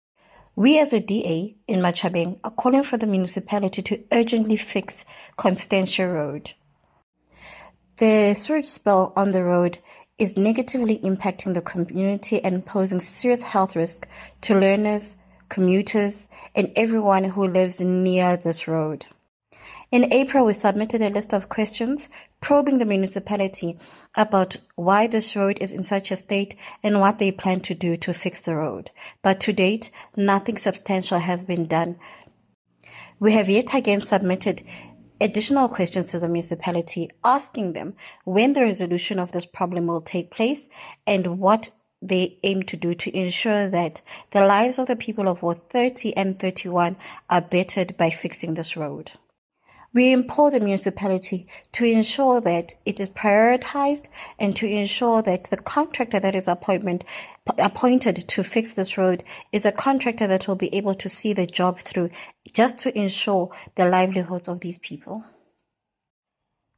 English soundbite by Cllr Zivumile Fandaleki and